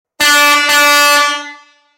Buzina Marítima Grande
Tem como característica técnica o som grave, baixa frequência e o longo alcance estando em conformidade com as exigências da Capitania dos Portos.
• Intensidade sonora 130db;
• Som grave, baixa frequência e longo alcance;
Som da Buzina